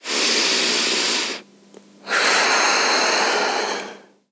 Mujer relajándose (respiración)
tranquilo
respirar
Sonidos: Acciones humanas
Sonidos: Voz humana